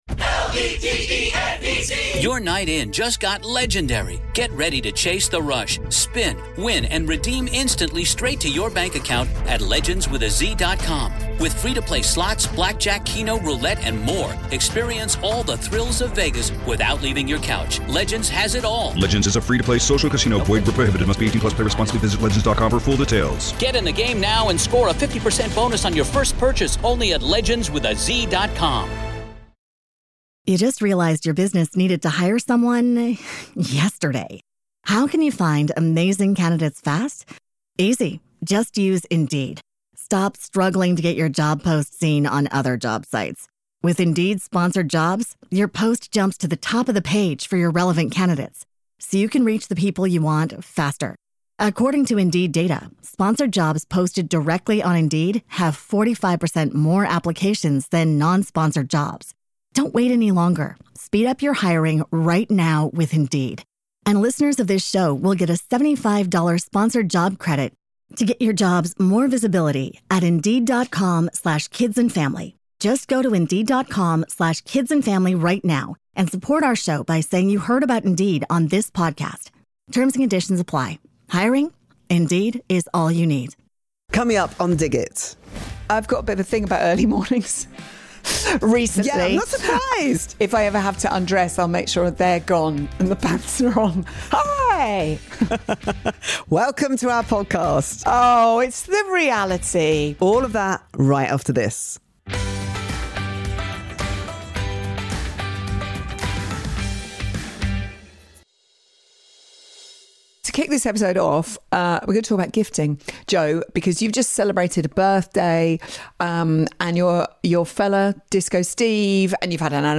This week, it’s a special Q&A edition of Dig It as Jo Whiley and Zoe Ball answer your questions — from terrible presents and touching gestures to early morning routines, long-body swimwear and their favourite everyday fashion finds.